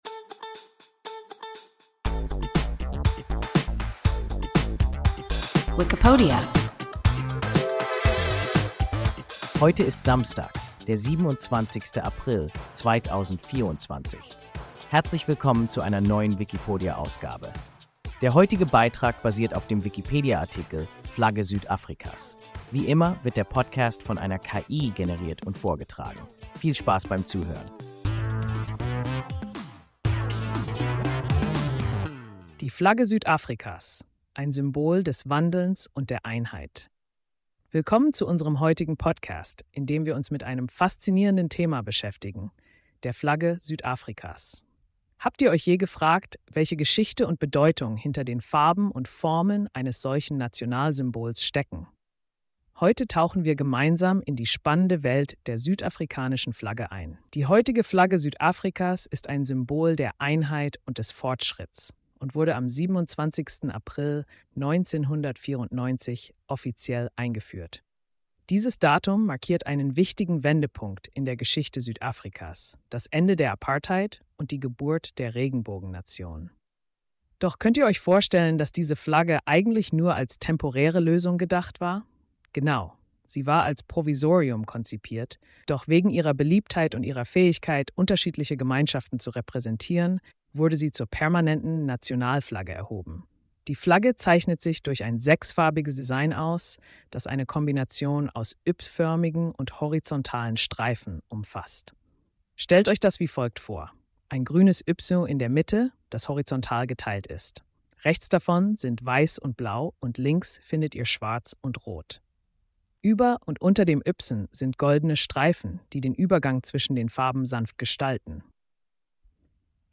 Flagge Südafrikas – WIKIPODIA – ein KI Podcast